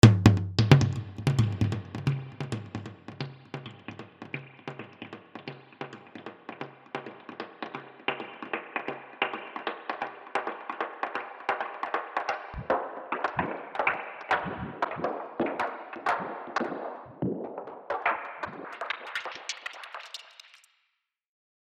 и мой маленький (не головокружительный) аудио пример (все родное, и модуляция в DAW)
Tom DUB.mp3